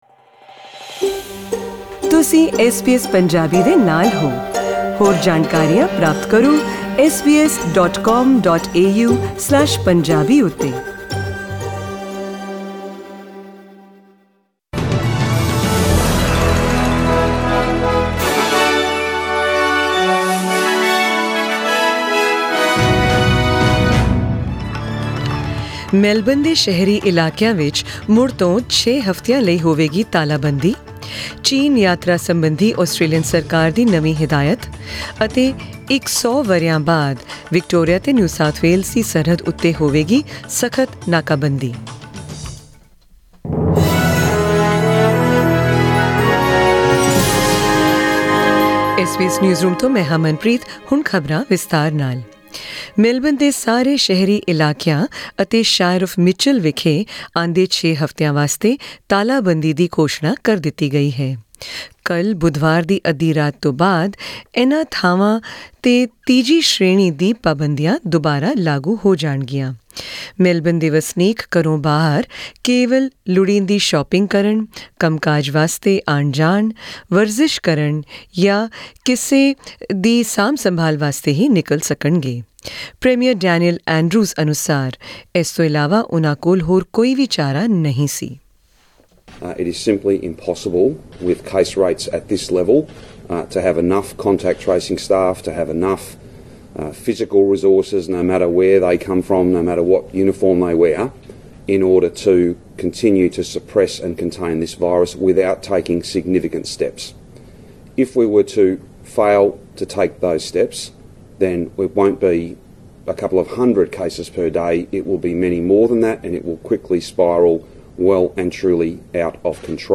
Presenting tonight's SBS Punjabi news bulletin, with news and information related to COVID-19 and more, from Australia and beyond.